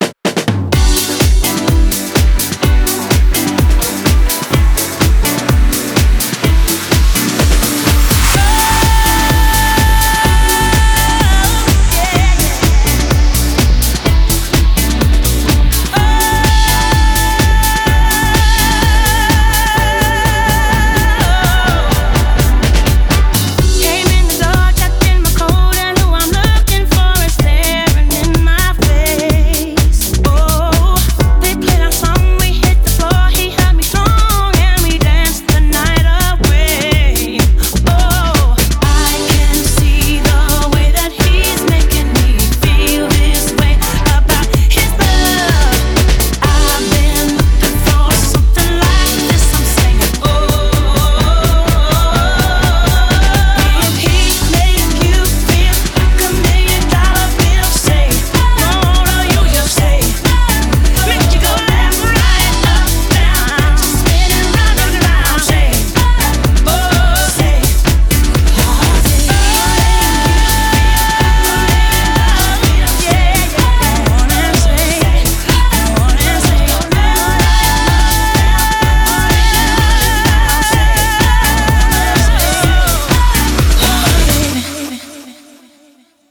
BPM126